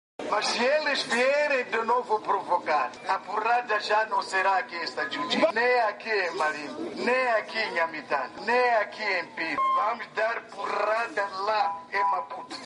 Num comício popular, Dhlakama reiterou a sua intenção de governar as províncias onde foi vencedor nas eleições de Outubro de 2014.